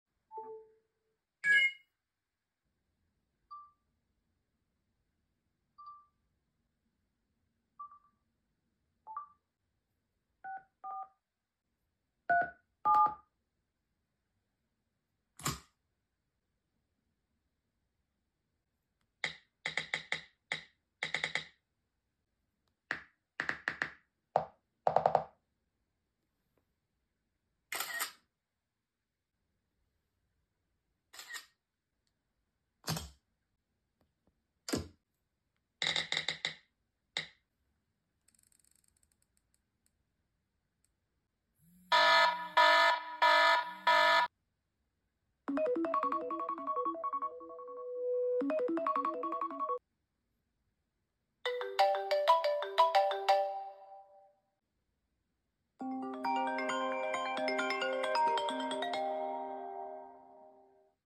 iPhone 16 Pro vs. iPhone 4 Sound Comparison